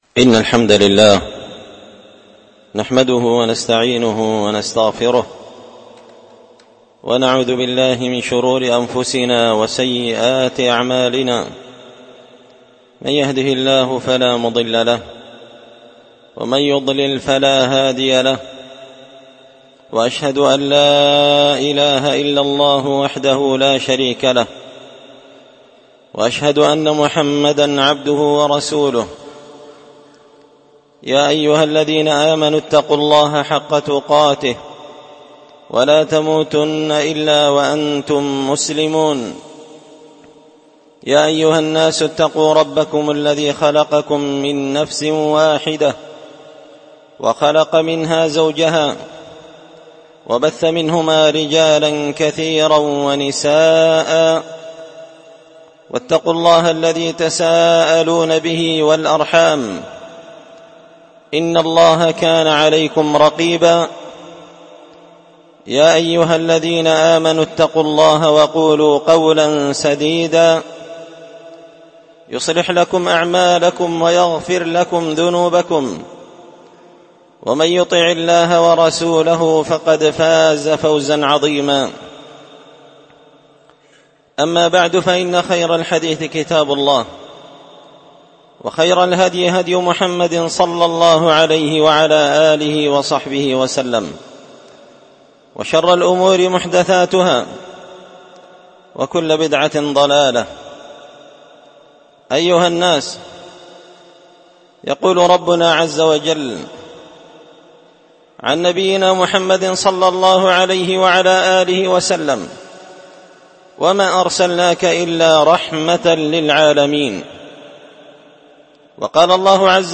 من الأرشيف خطبة جمعة بعنوان:
ألقيت هذه الخطبة بدار الحـديـث السلفية بمـسجـد الفـرقـان قشن-المهرة-اليمن تحميل